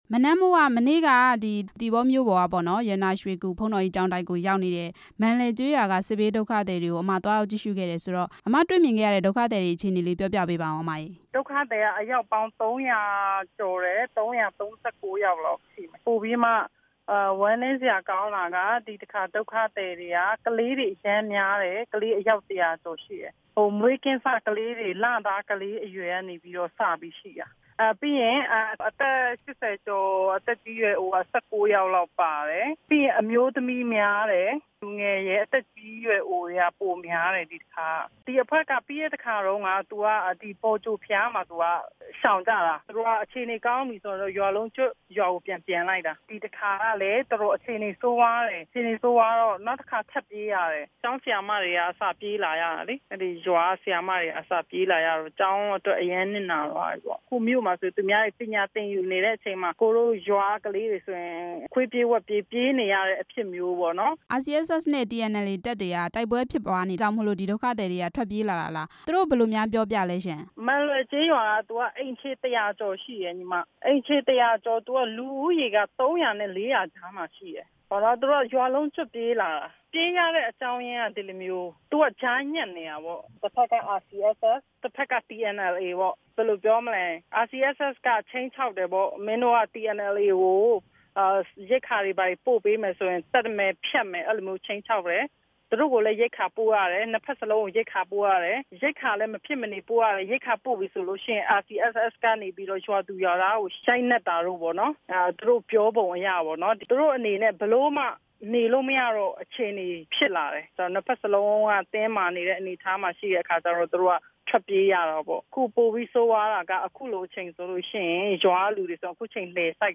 ဒေါ်နန်းမိုး ကို ဆက်သွယ်မေးမြန်းချက်